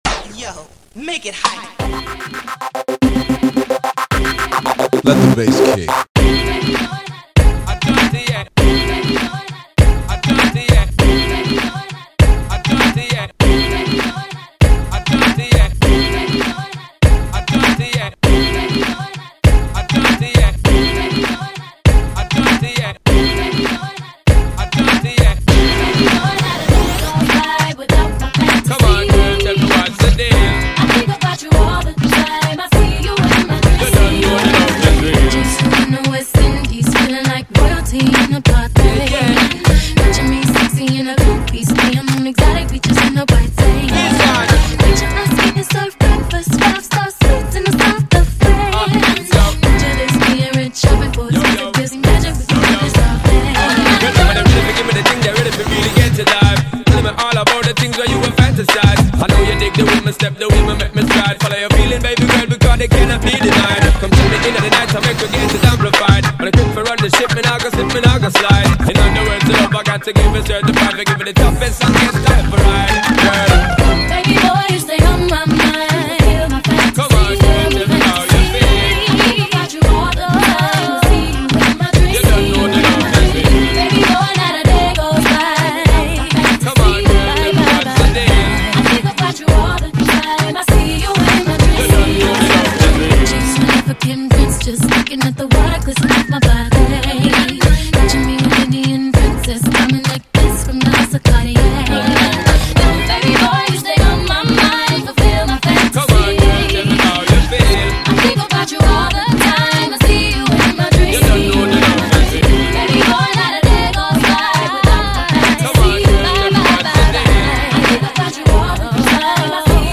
dance/electronic
RnB